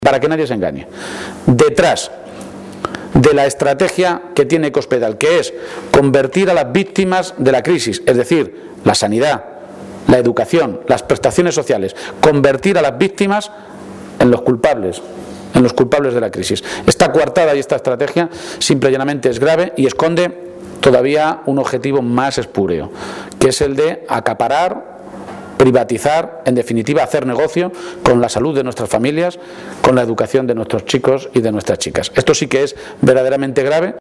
García-Page ha realizado estas declaraciones en Puertollano, localidad en la que esta tarde tiene previsto reunirse con colectivos y sindicatos para analizar la tremenda situación por la que está atravesando la ciudad industrial.